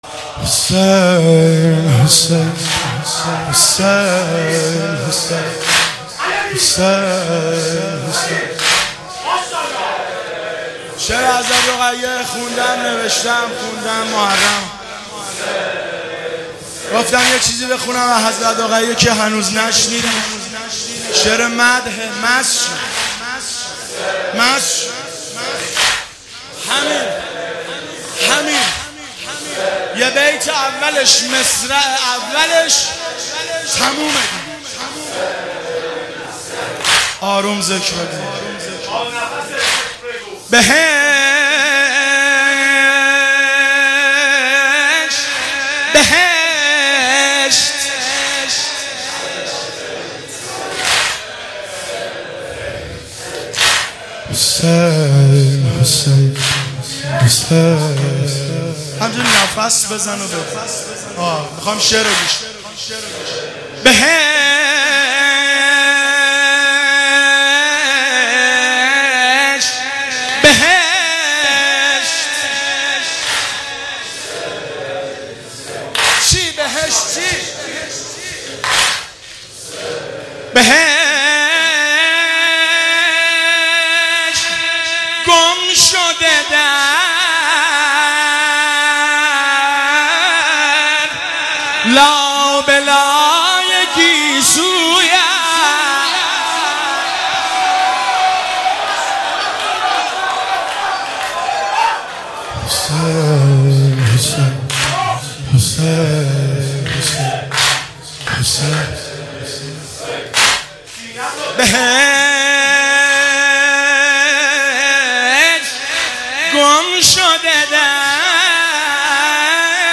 • شعرخوانی